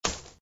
chip.mp3